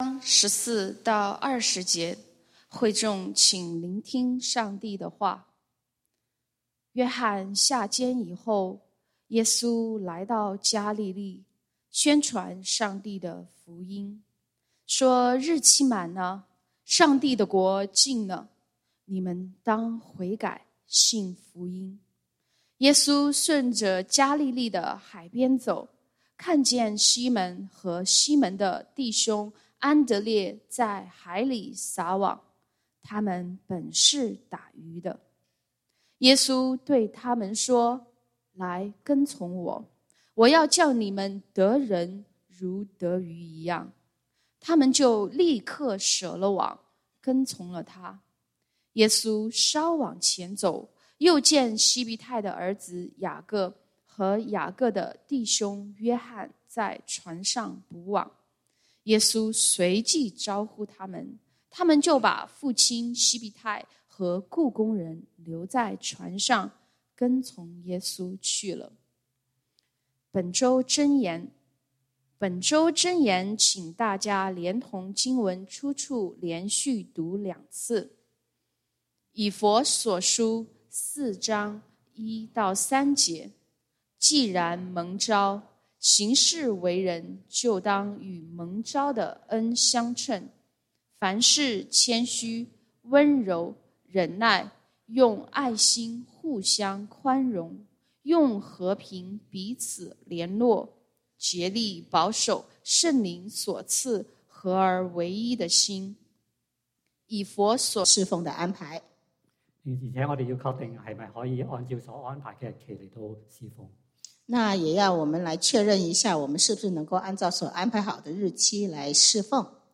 講道經文：《馬可福音》Mark 1:14-20 本週箴言：《以弗所書》Ephesians 4:1-3 「既然蒙召，行事為人就當與蒙召的恩相稱。